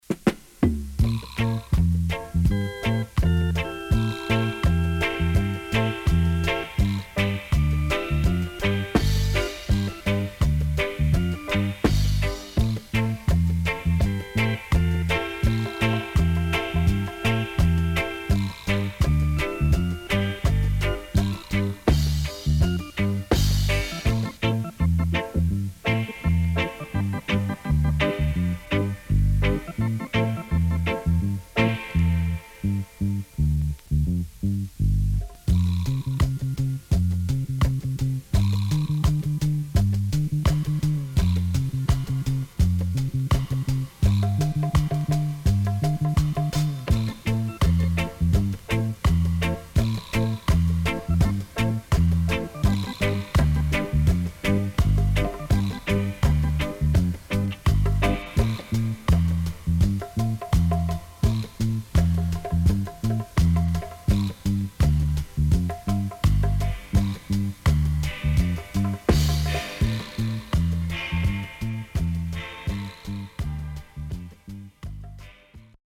SIDE A:少しノイズ入りますが良好です。
SIDE B:少しノイズ入りますが良好です。